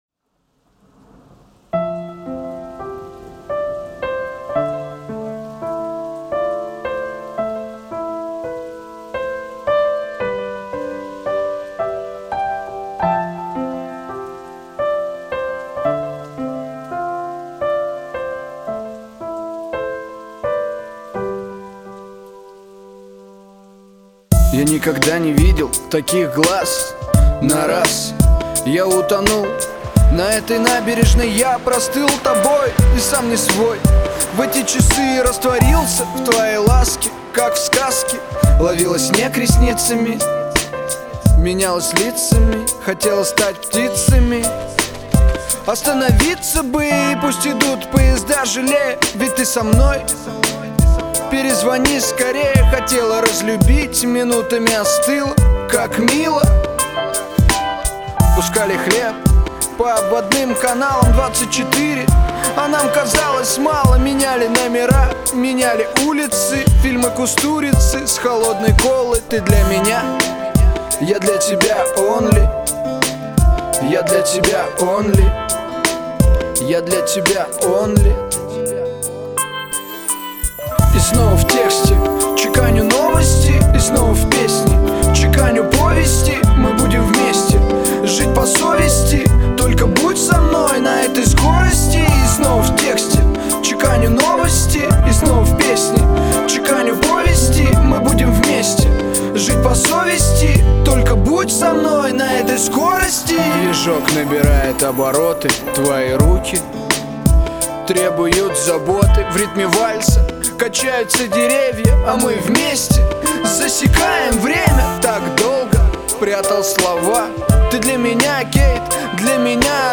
Жанр: Рэп (Хип-хоп)